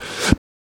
Mouth Interface (9).wav